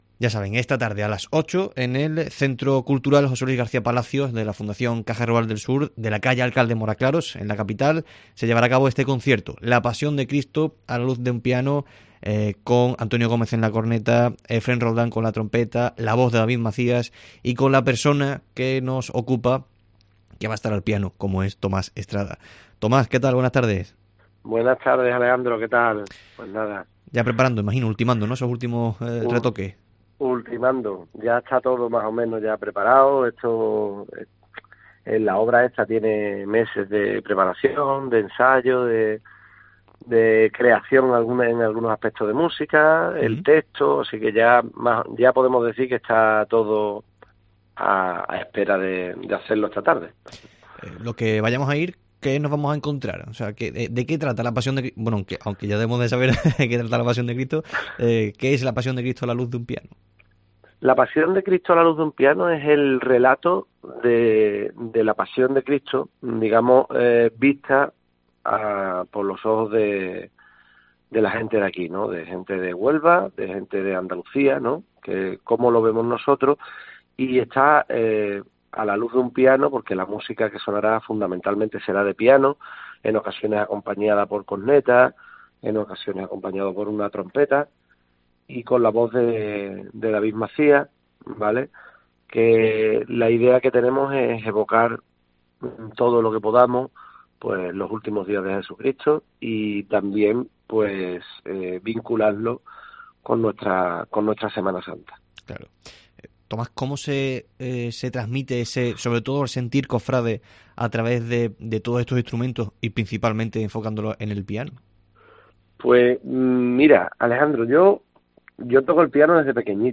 En el Mediodía COPE de este miércoles hemos hablado con el pianista